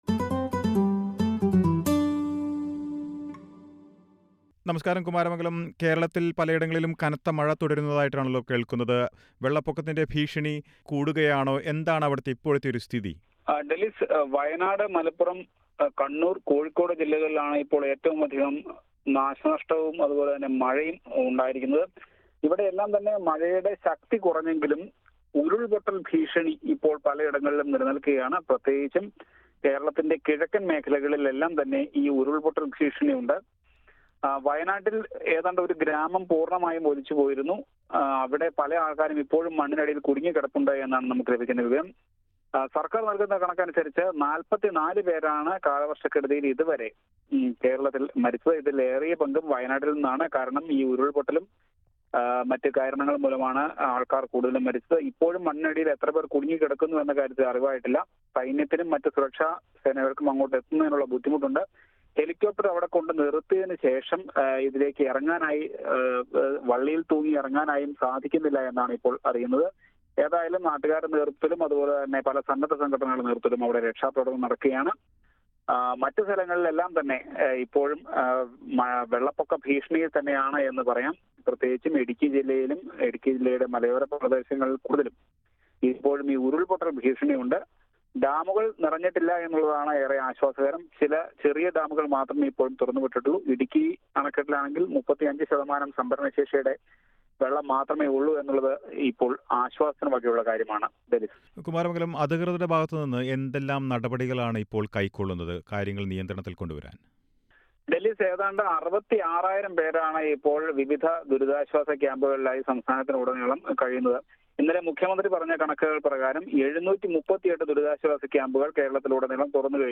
india_report.mp3